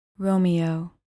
Pronounced: ROW-me-oh